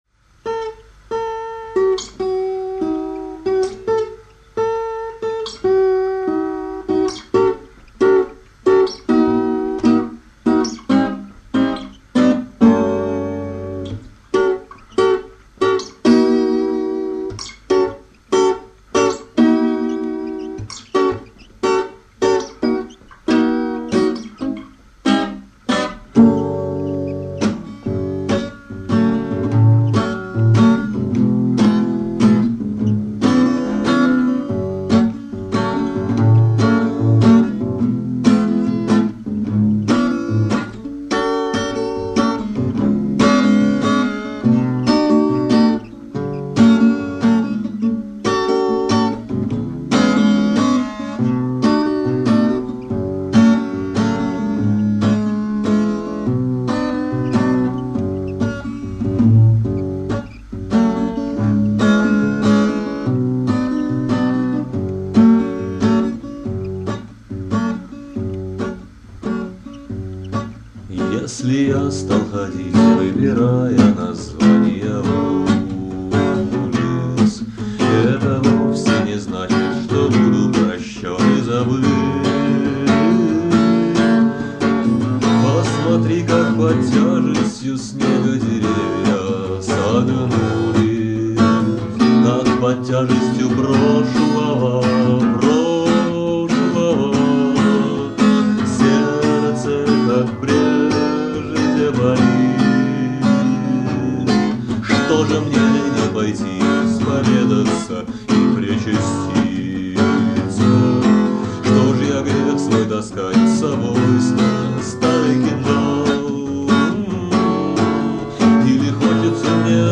песни 1992-97 гг. в исполнении автора.